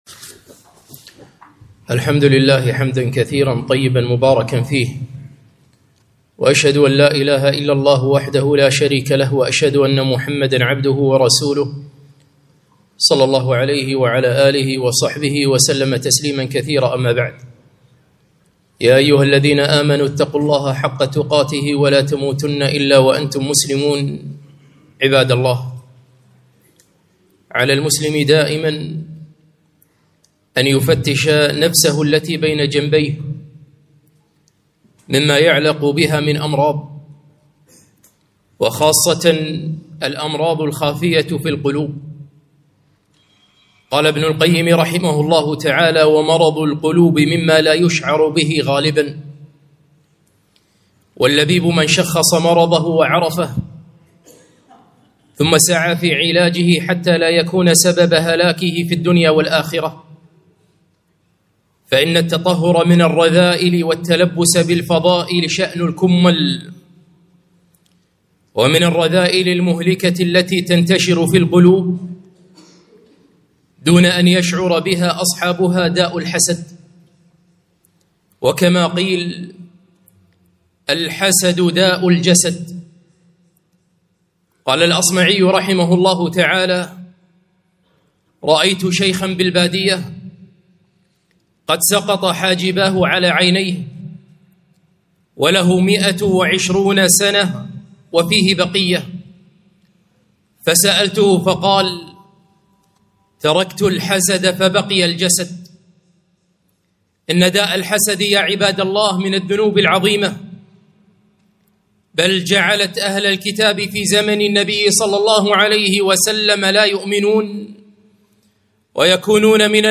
خطبة - الحسد داء الجسد